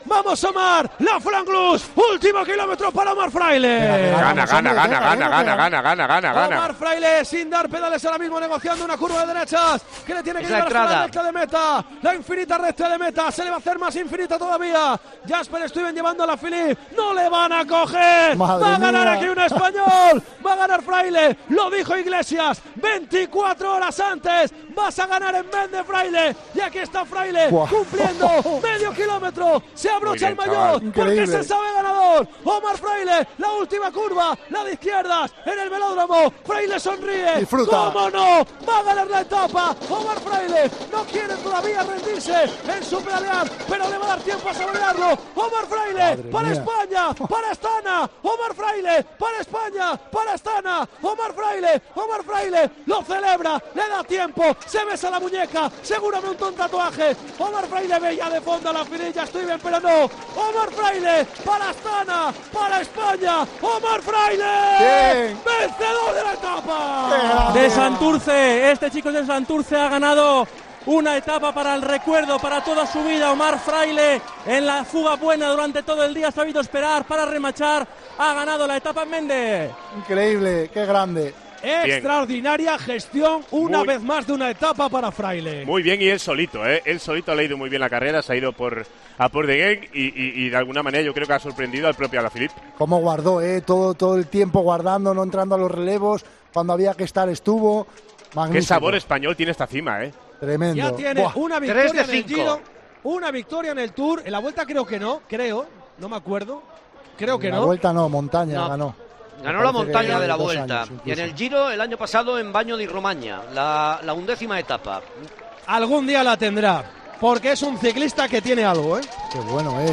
Así vivimos en Tiempo de Juego la victoria de Omar Fraile en la 14ª etapa del Tour